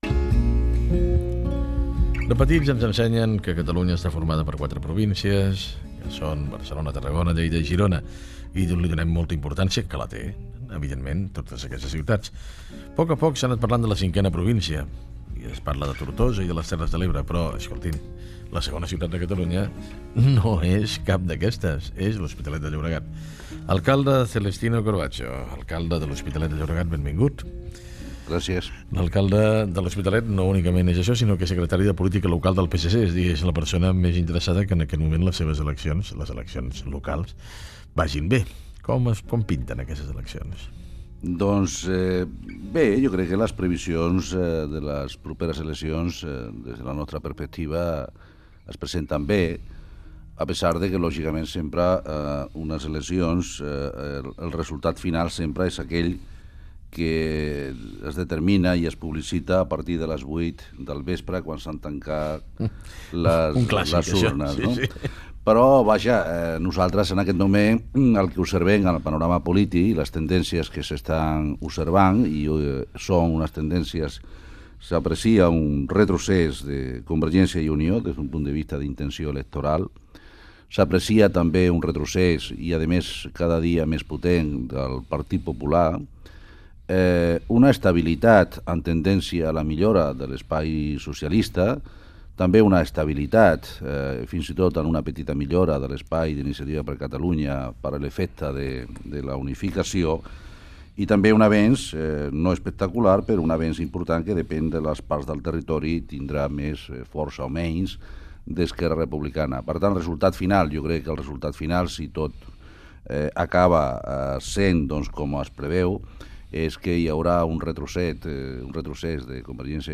Fragment d'una entrevista a l'alcalde de l'Hospitalet de Llobregat Celestino Corbacho.
Info-entreteniment